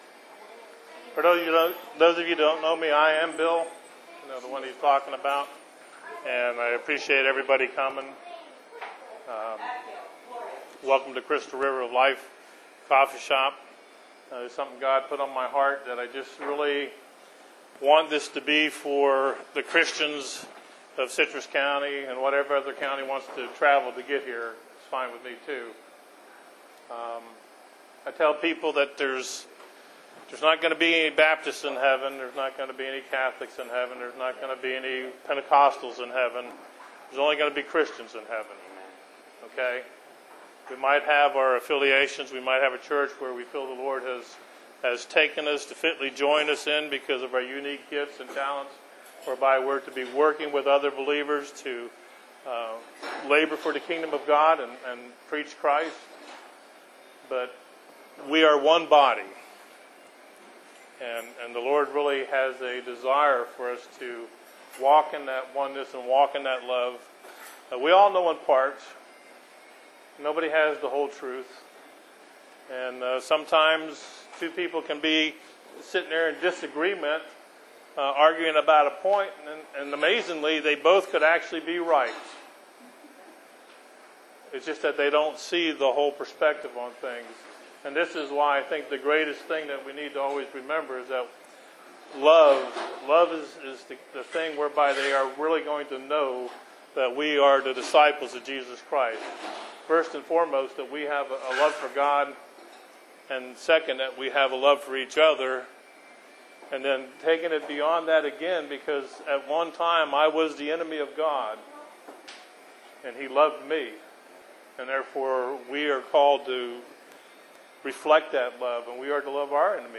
1 Message shared at the end of the evening stressing our need, even under the Gospel, to wait on the Lord.